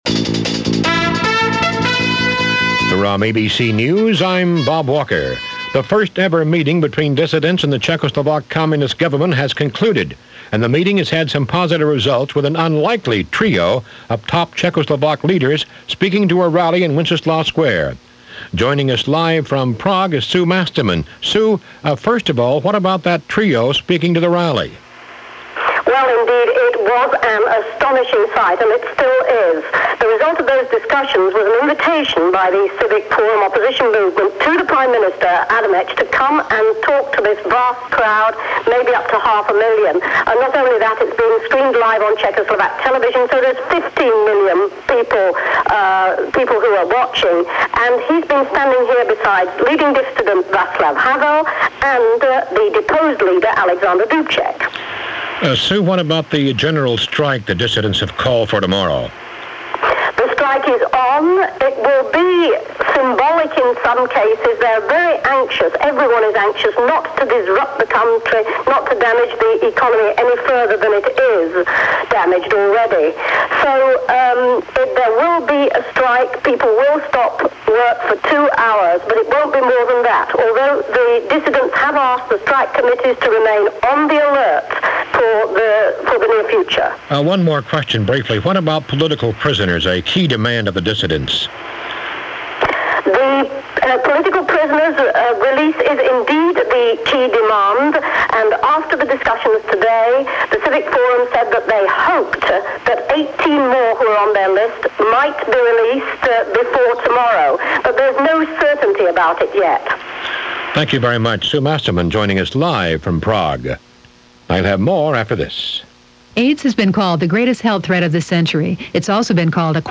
And while the news was devloping in Prague and throughout Eastern Europe, that’s a little of what went on this November 25th in 1989 as reported by ABC Radio News.